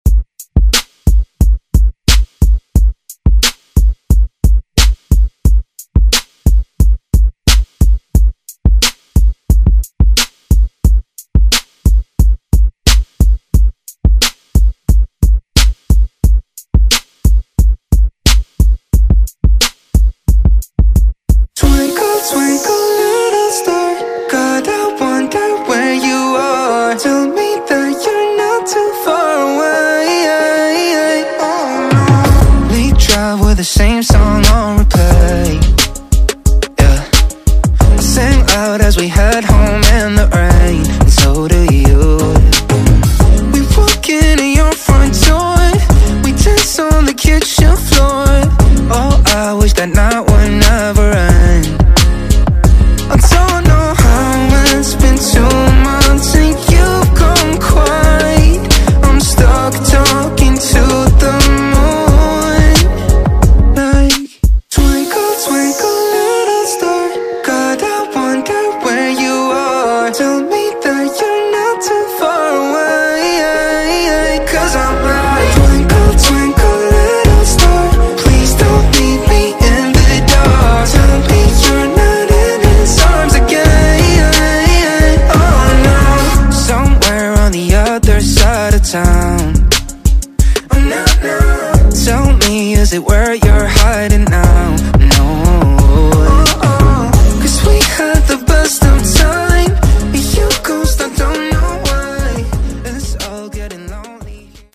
Genres: AFROBEAT , DANCE , RE-DRUM
Clean BPM: 124 Time